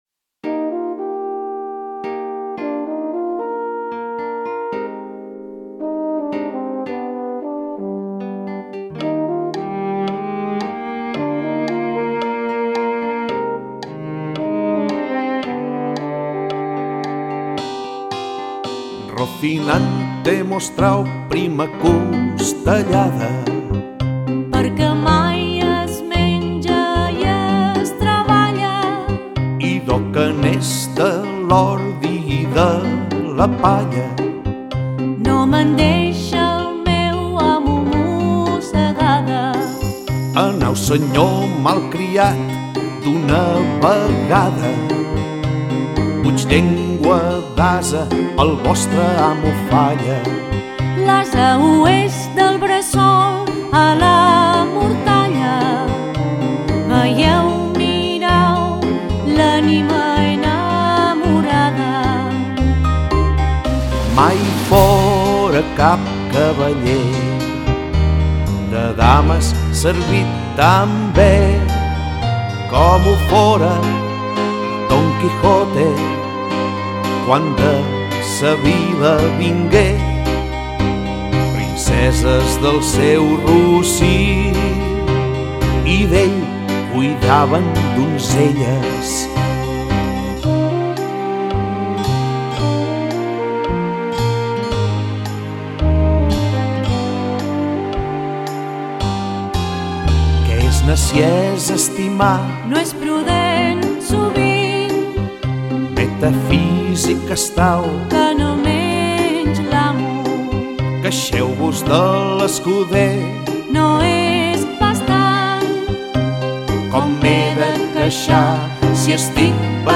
adaptándola a ritmos modernos
El resultado es este vibrante CD lleno de vida y buen gusto.